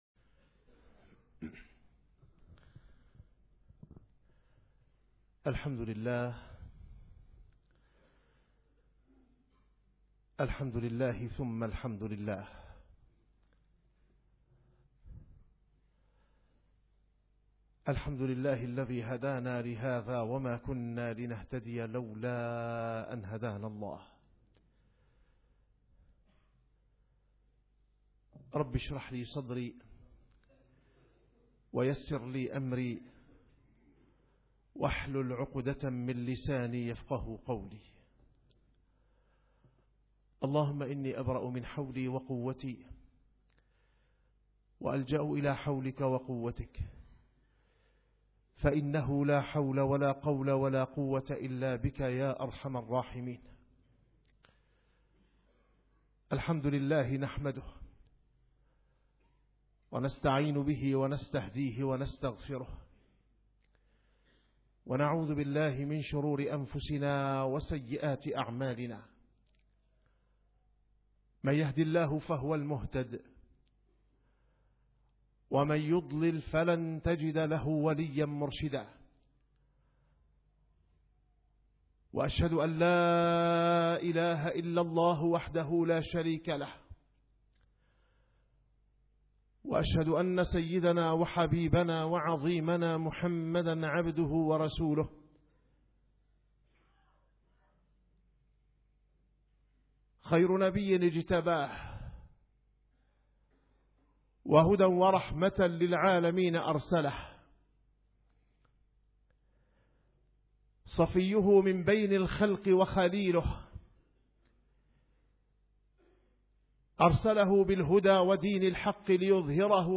- الخطب - في ذكرى ولادة السيد الأعظم-الحب النبوي-لماذا الحب أولاً